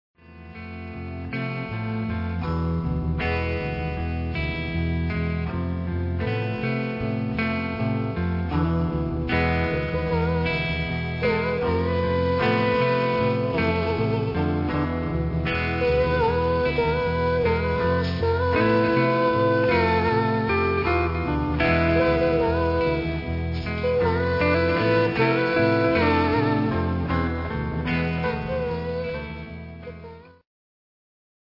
レディス・サイケデリックの新鋭、